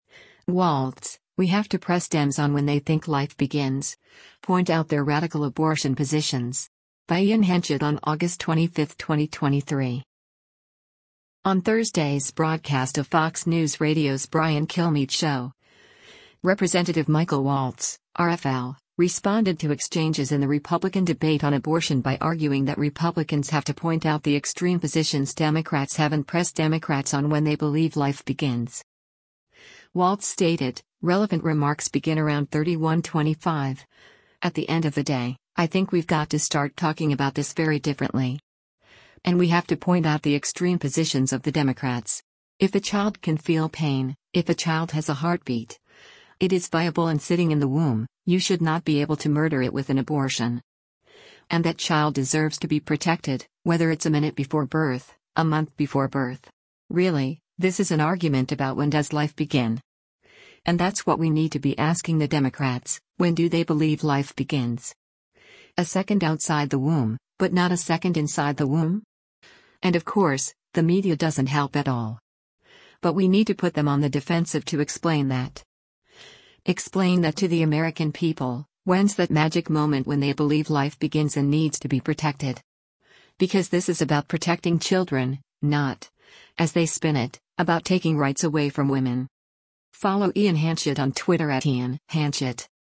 On Thursday’s broadcast of Fox News Radio’s “Brian Kilmeade Show,” Rep. Michael Waltz (R-FL) responded to exchanges in the Republican debate on abortion by arguing that Republicans have to point out the extreme positions Democrats have and press Democrats on when they believe life begins.